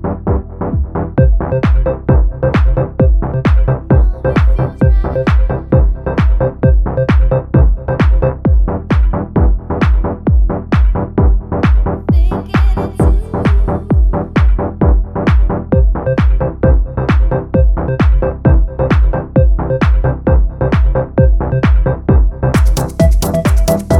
no Backing Vocals Dance 3:26 Buy £1.50